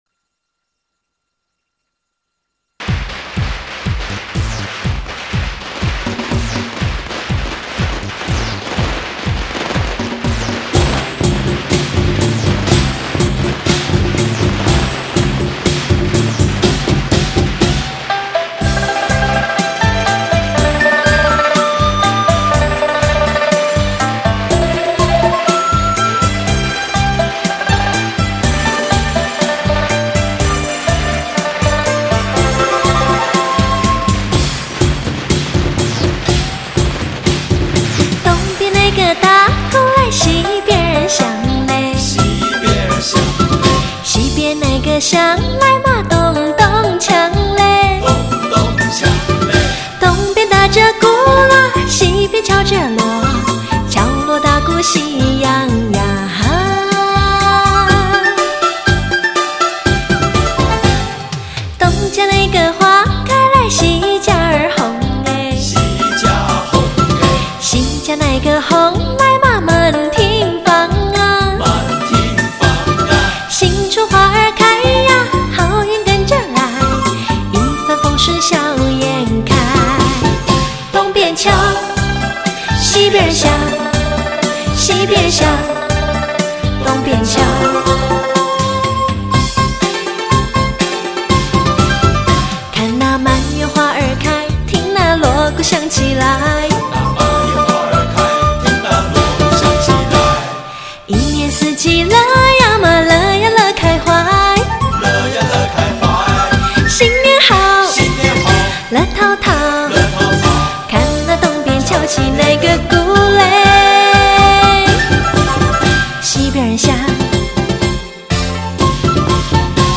音乐类型: 汽车音乐
3D音效+环绕360度专业汽车HI-FI音乐SRS+HD。